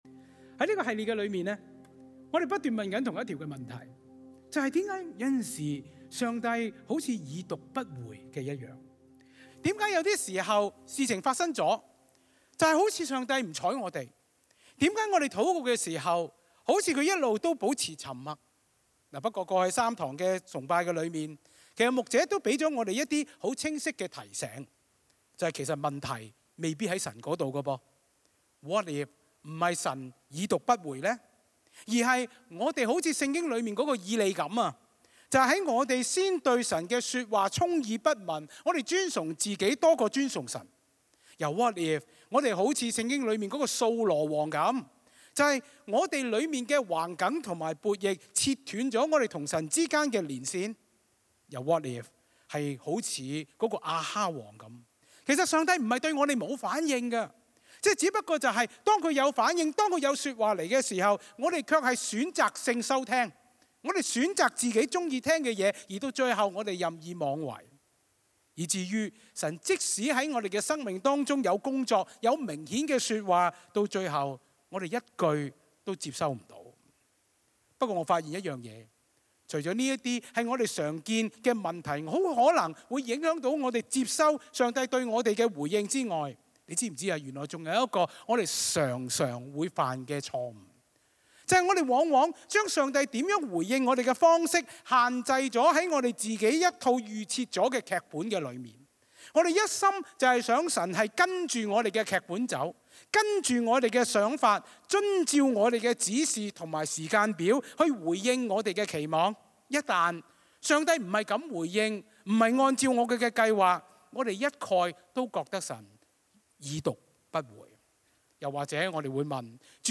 講道搜尋 | Koinonia Evangelical Church | 歌鄰基督教會